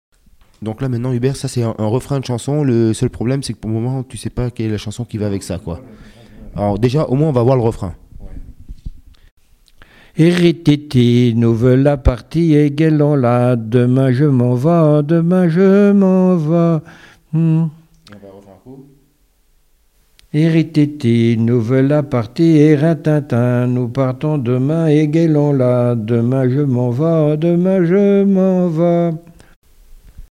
Témoignages et chansons
Pièce musicale inédite